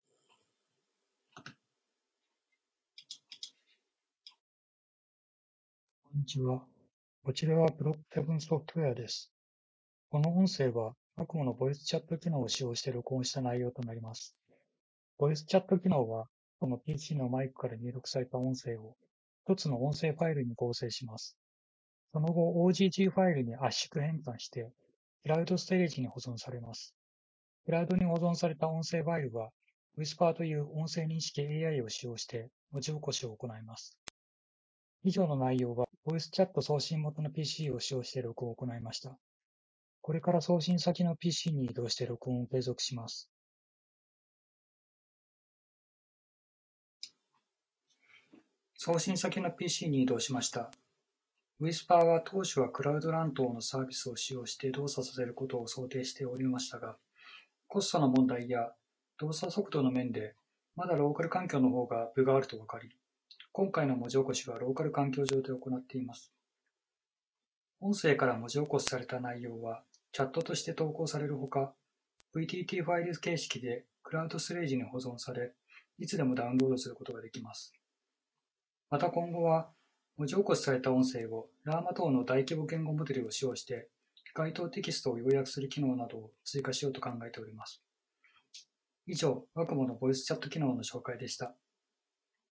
• 自動音声録音機能-ボイスチャットの音声を自動で録音します。